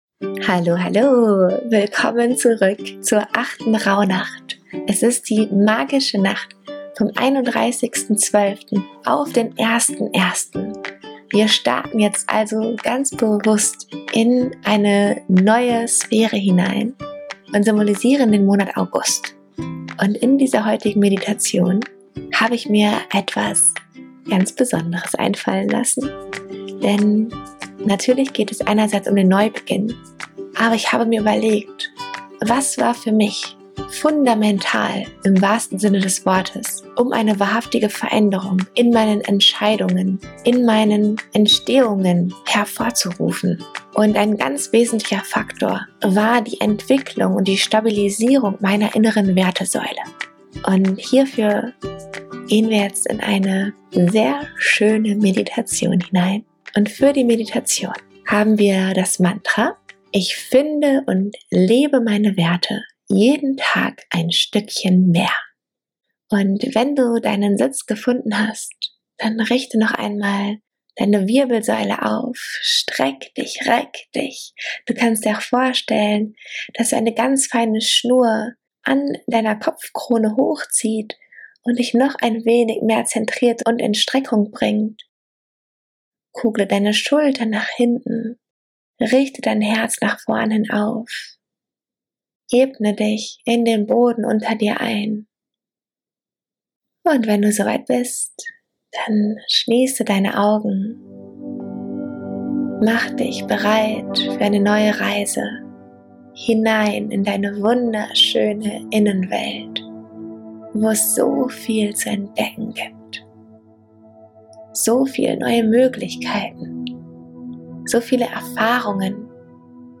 8. Rauhnächte Meditation - Finde und lebe deine Werte ~ Weil du Liebe bist.